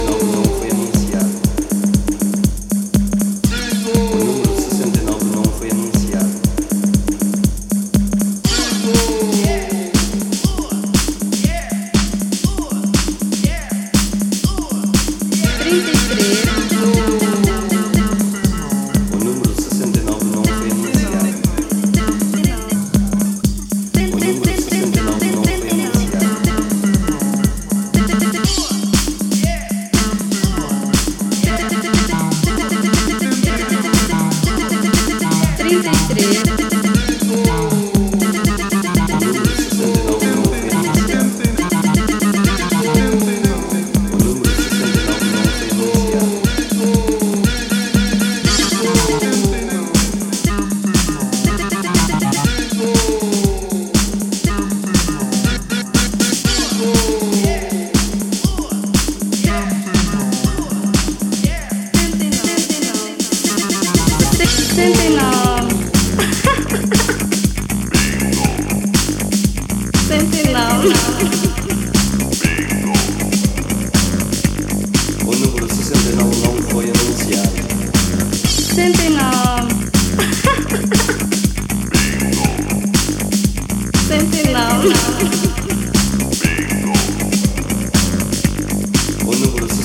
House Italo